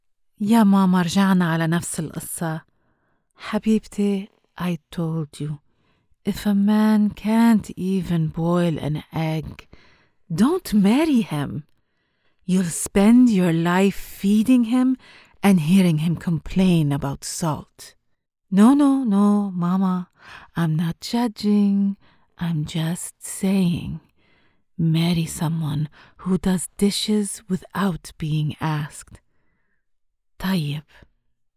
Character Samples
Arabic-Mom.mp3